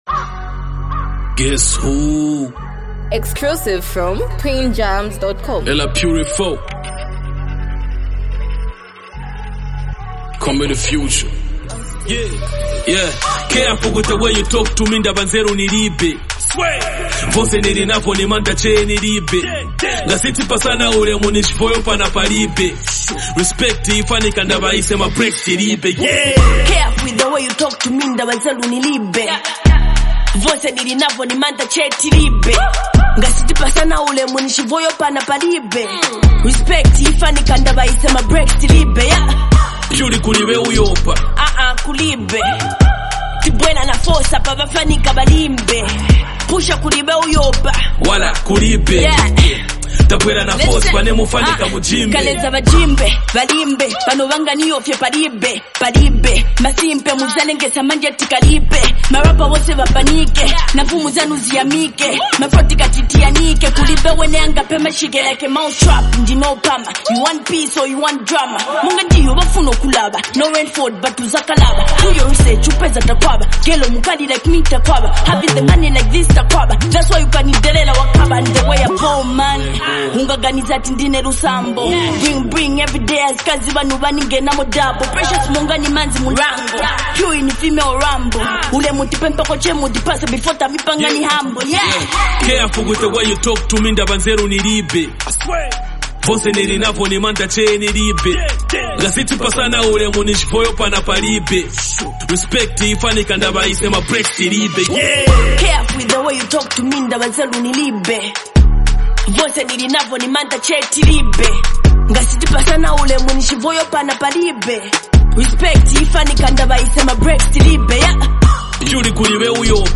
street-rooted, emotional song